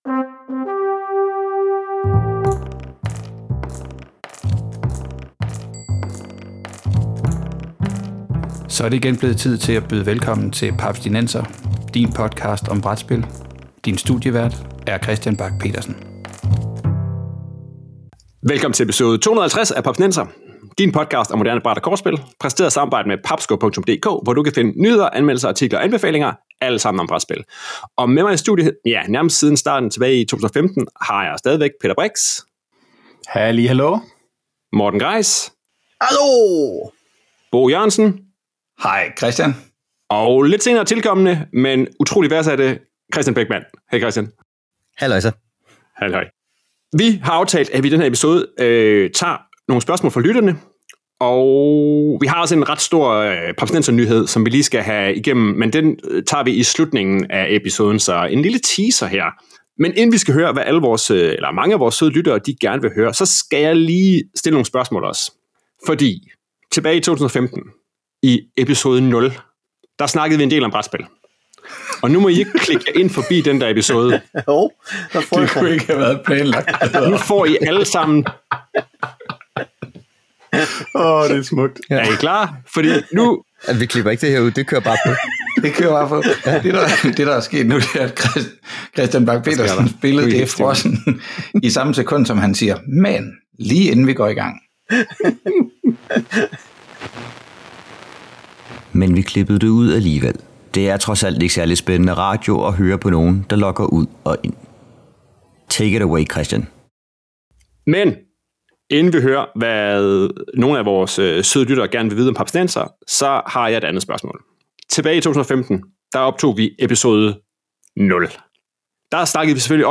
Det fejres med spørgsmål fra lytterne og vi kommer kommer godt rundt i podcastens hjørner og kroge – inklusiv fraklip… der i dagens anledning IKKE er klippet ud.